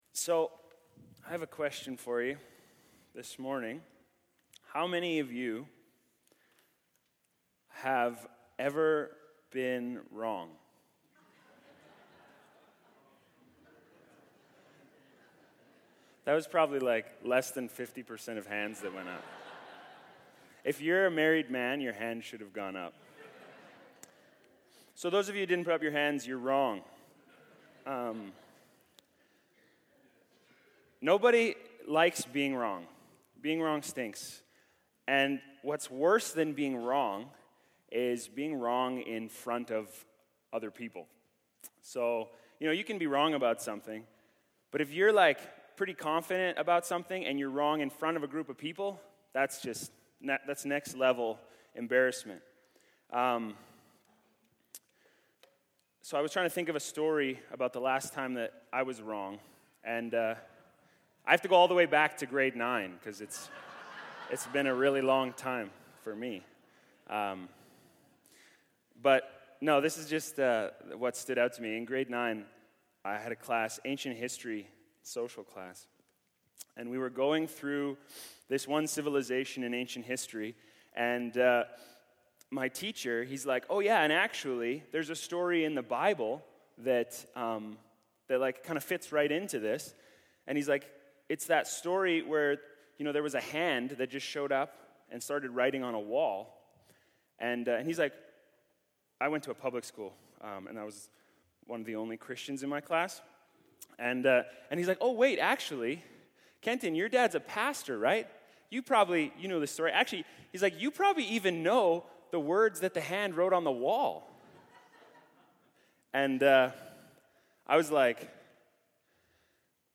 Sermons | Mount Olive Church
Guest Speaker